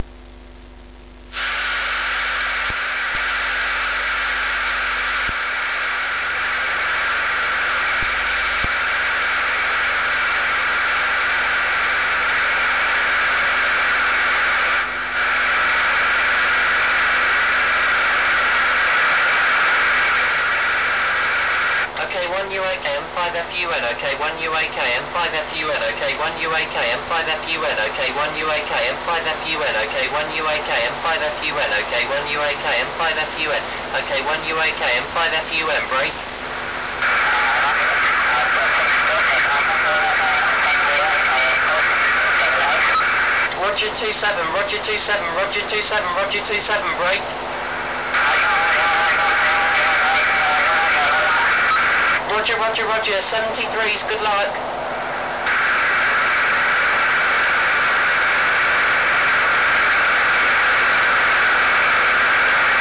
Below are some sound files that will let you hear what amateur radio can really be like.
Sound of a brief reflection off of a meteor - August 2002